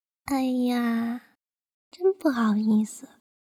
女生羞涩说多不好意思音效_人物音效音效配乐_免费素材下载_提案神器